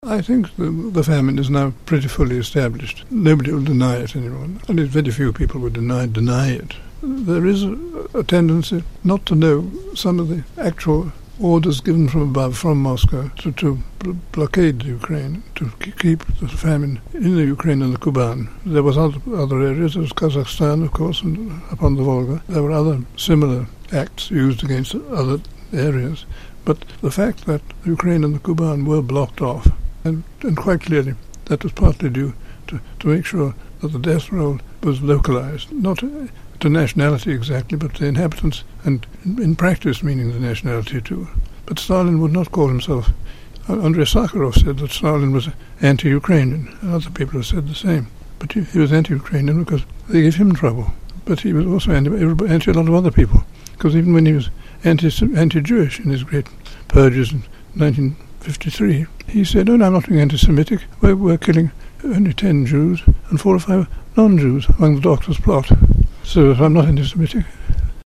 RFE/RL Interview: Robert Conquest On 'Genocide' And Famine